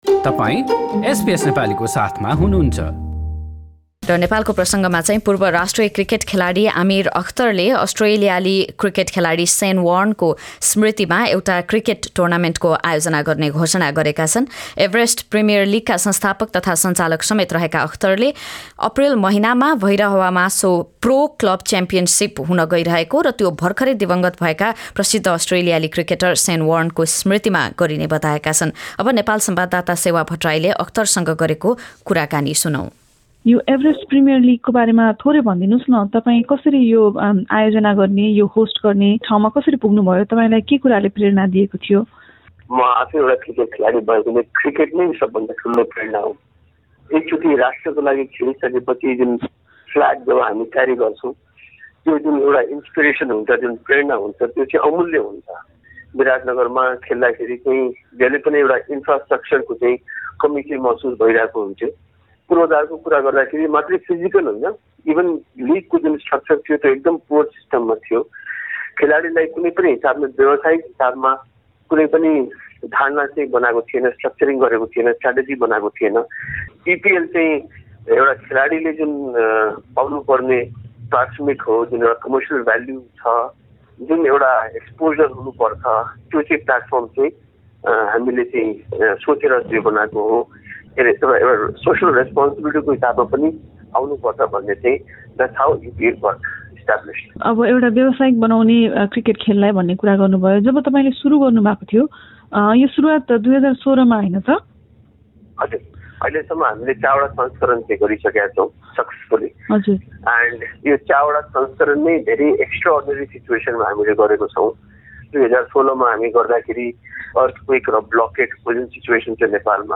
कुराकानी सहितको रिपोर्ट